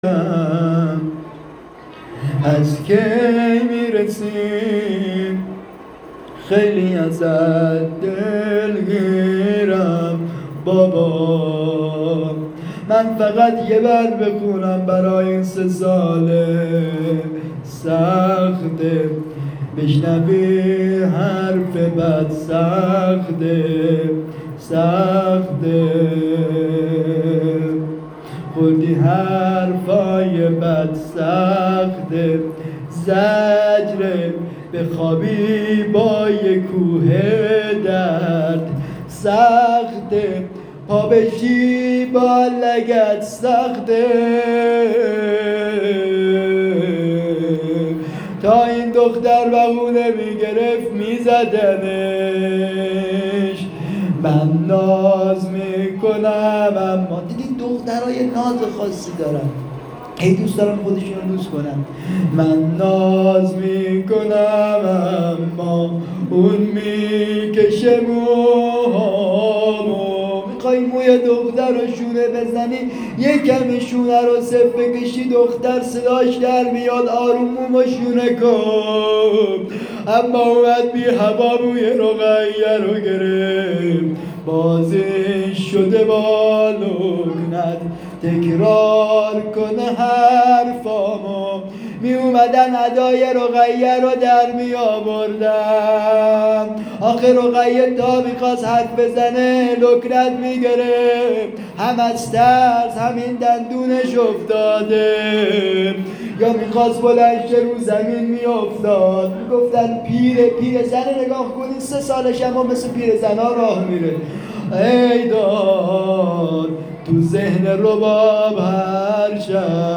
روضه حضرت رقیه